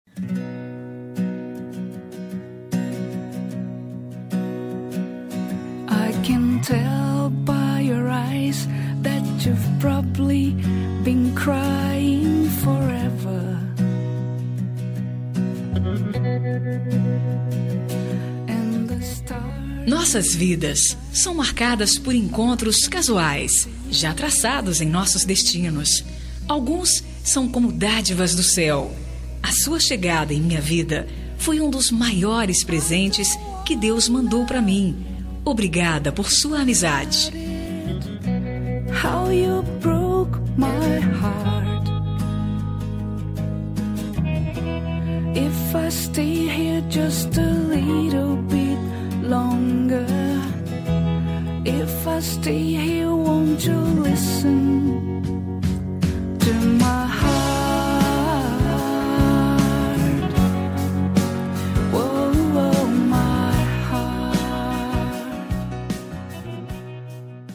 Telemensagem Amizade – Voz Feminina – Cód: 031009 – Amizade